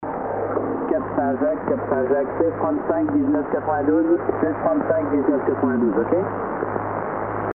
Des voix du radiomaritime